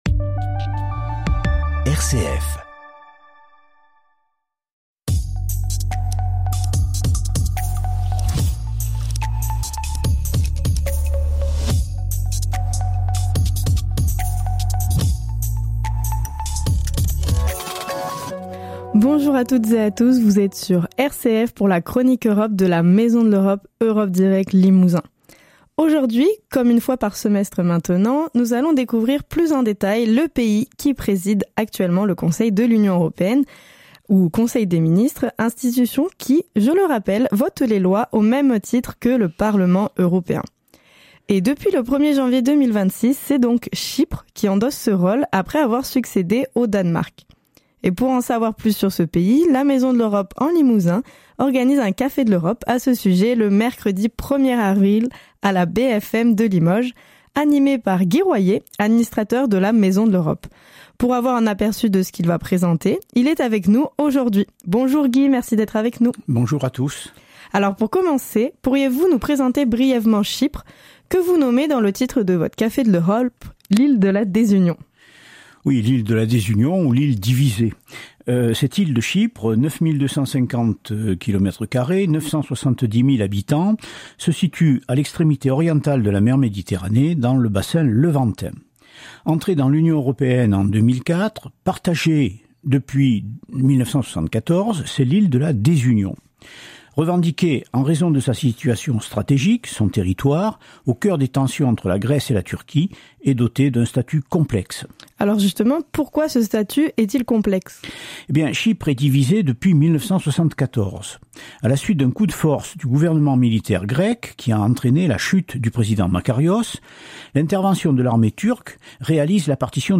chypre , conseil de l'ue , présidence , radio